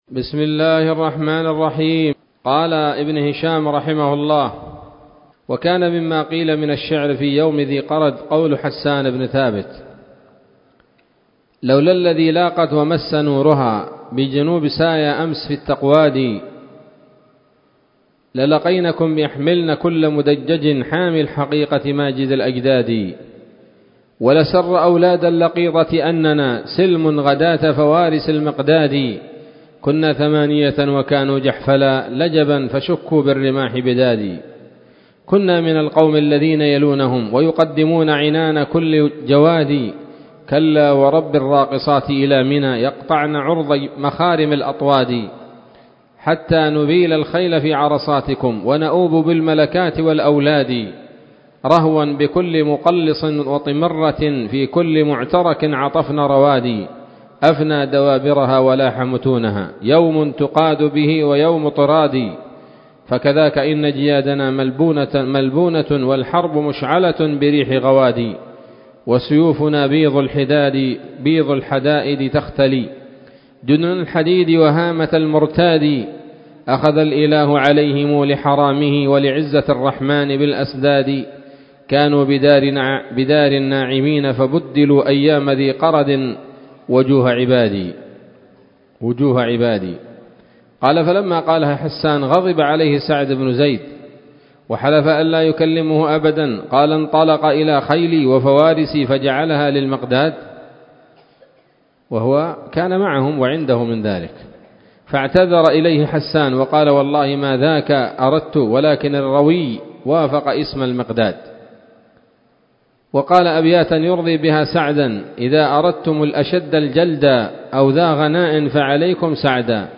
الدرس الثاني والعشرون بعد المائتين من التعليق على كتاب السيرة النبوية لابن هشام